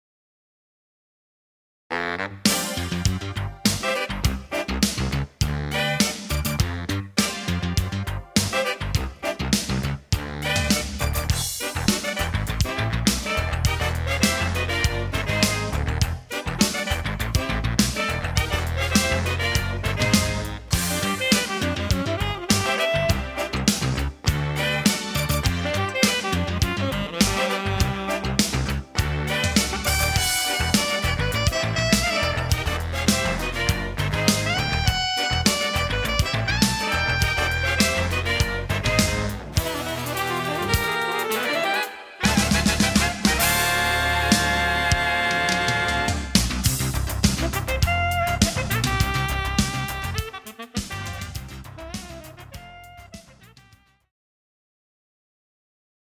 Categories » Saxophone » Saxophone Quartets